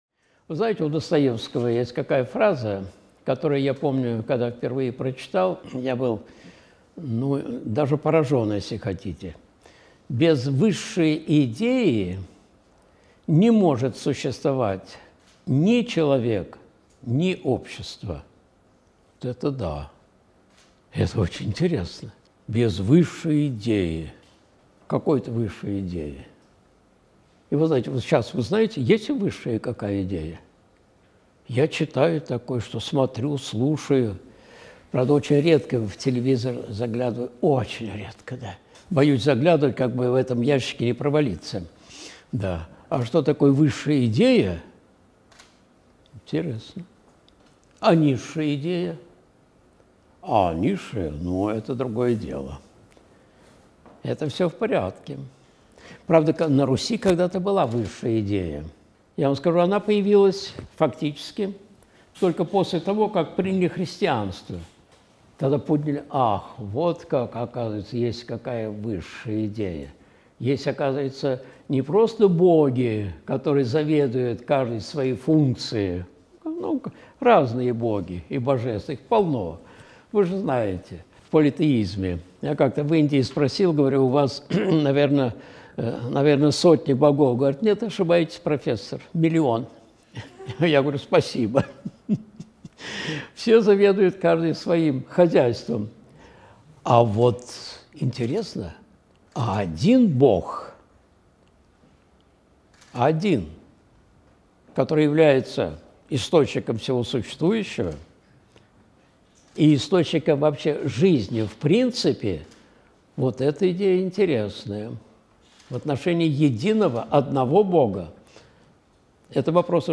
Куда несёт поток жизни? (Сретенский монастырь, 13.02.2024)
Видеолекции протоиерея Алексея Осипова